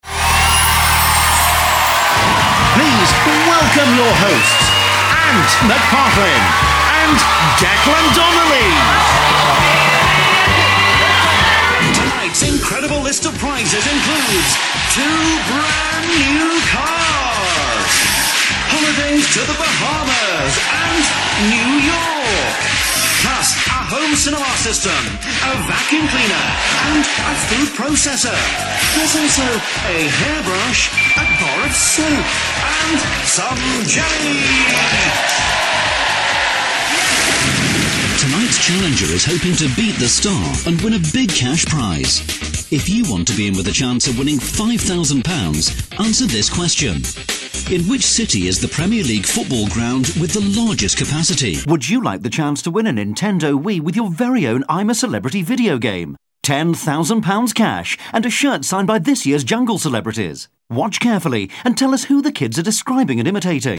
Friendly, natural yet corporate sounding neutral British English voice.
Sprechprobe: Sonstiges (Muttersprache):
Friendly, natural corporate neutral UK English.